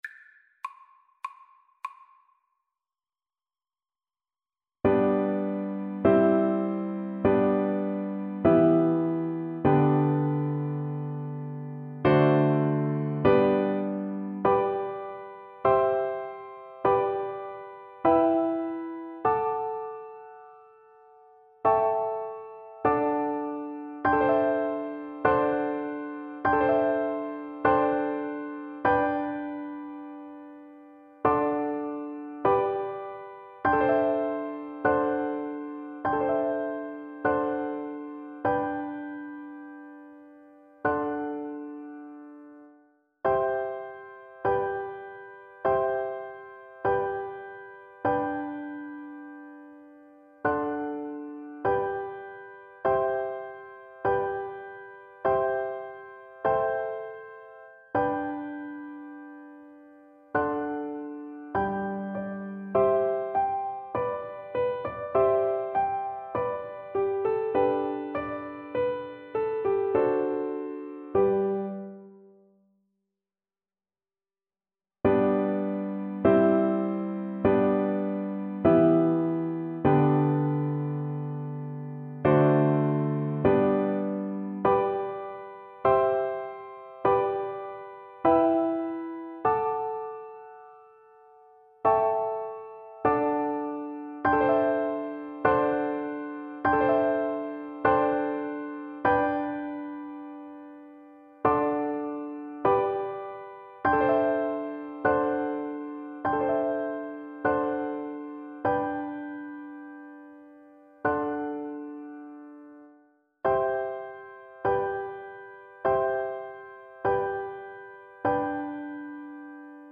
Moderato
Classical (View more Classical Flute Music)